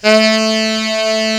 Index of /90_sSampleCDs/Giga Samples Collection/Sax/HARD + SOFT
TENOR HARD A.wav